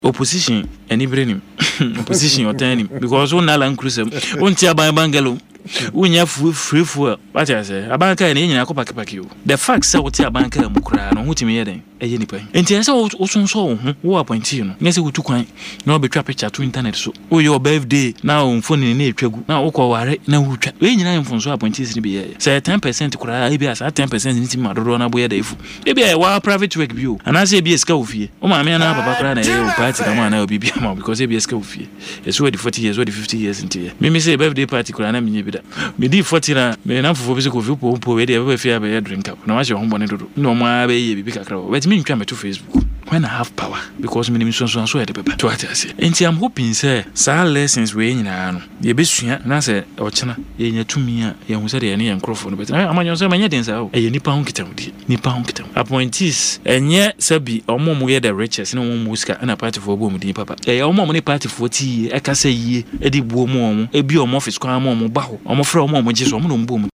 Speaking on Adom FM’s Burning Issues, the NPP stalwart cautioned newly appointed officials to learn from these mistakes and adopt a more modest approach.